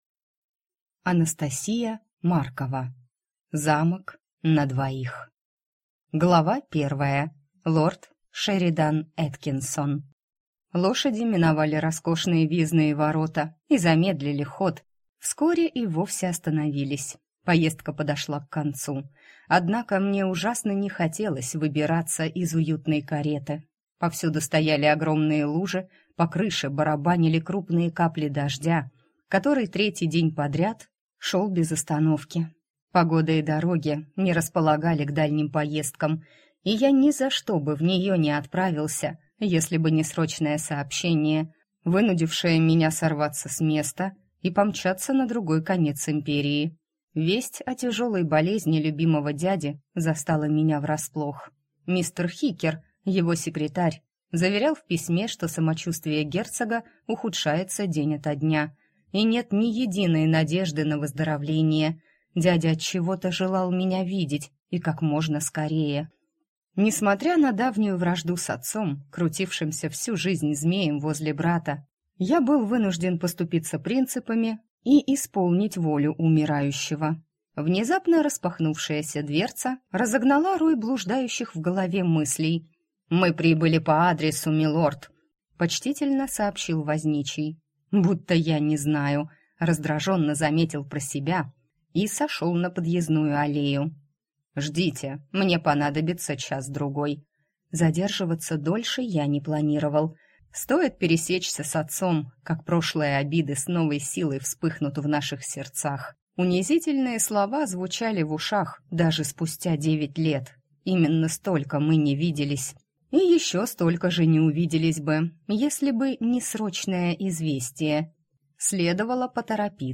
Аудиокнига Замок на двоих | Библиотека аудиокниг